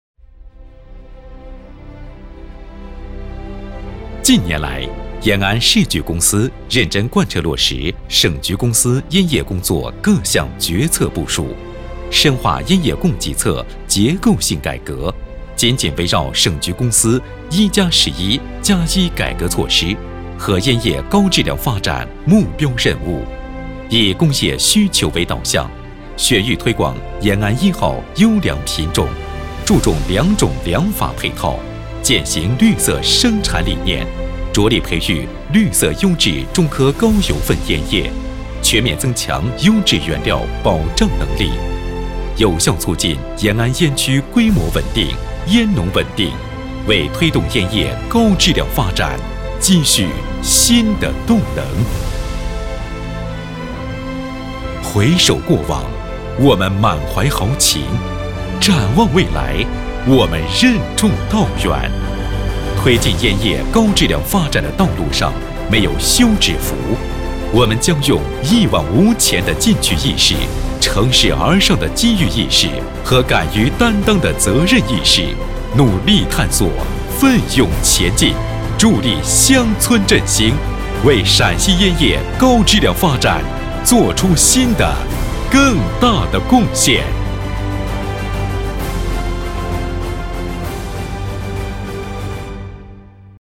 淘声配音网，专题，宣传片配音，专业网络配音平台 - 淘声配音网配音师男国语203号 大气 沉稳 厚重
配音风格： 大气 沉稳 厚重